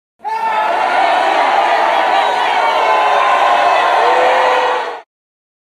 Crowd sound effect
Thể loại: Tiếng hoạt động con người
Description: Hiệu ứng âm thanh Crowd Sound Effects mô phỏng chân thực âm thanh của một đám đông, từ tiếng nói chuyện rì rầm, tiếng reo hò, vỗ tay, la hét, đến những âm thanh hỗn tạp trong một không gian đông người như sân vận động, lễ hội, buổi hòa nhạc, hội chợ hay đường phố sầm uất. Thể hiện sự náo nhiệt, phấn khích, ồn ào, hoặc ồn nền nhẹ nhàng, phù hợp với đa dạng bối cảnh.
crowd-sound-effects-www_tiengdong_com.mp3